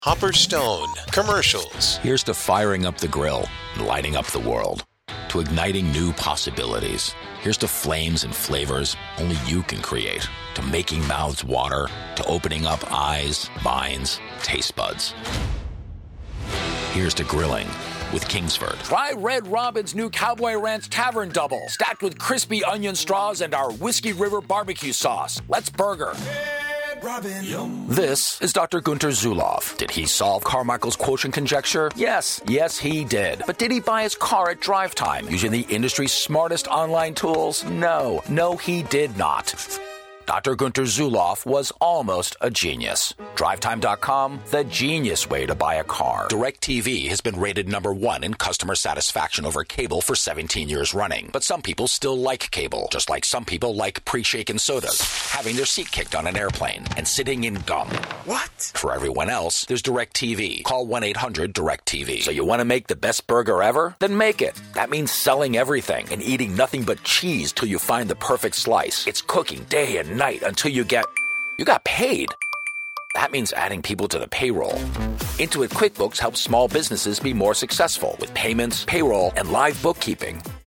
Commercials